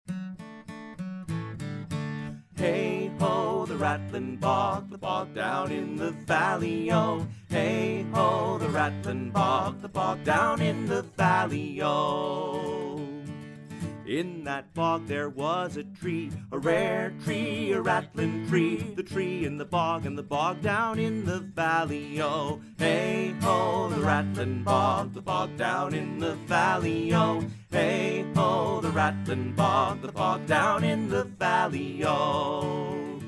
Traditional Children's Song Lyrics and Sound Clip
Folk Song Lyrics